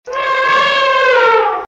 onomatopeia_elefante.mp3